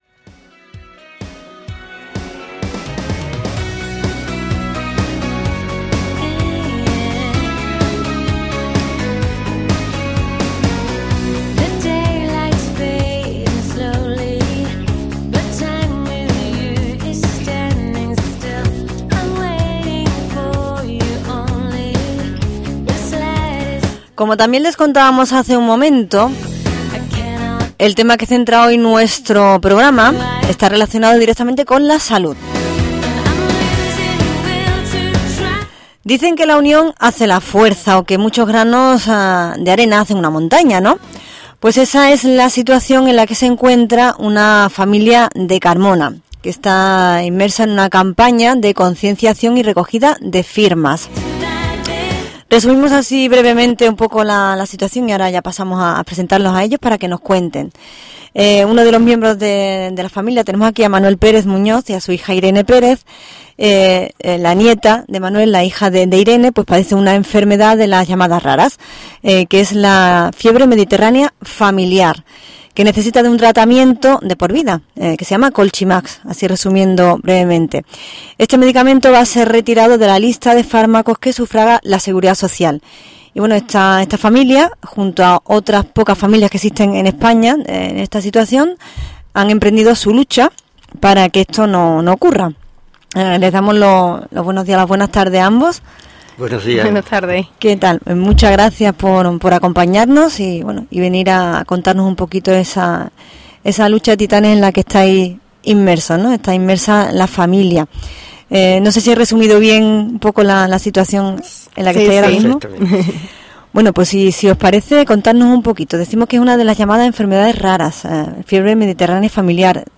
Entrevista en Radio Carmona